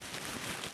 crumple3.ogg